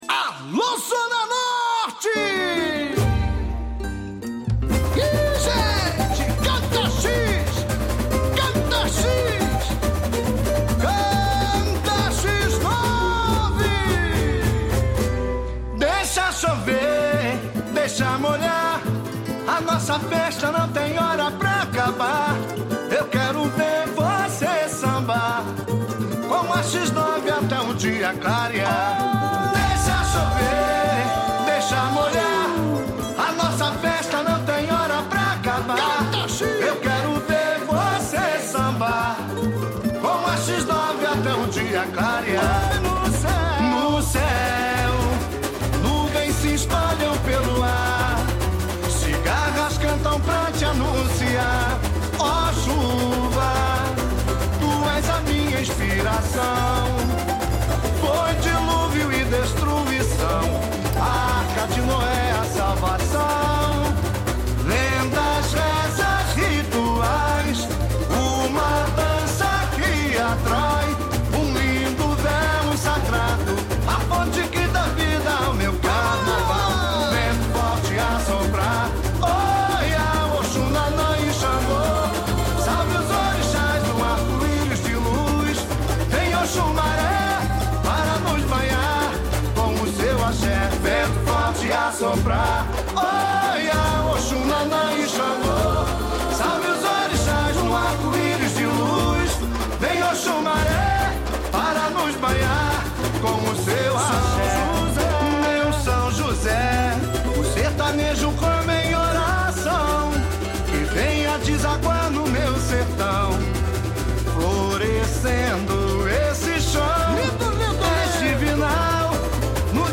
Intérprete: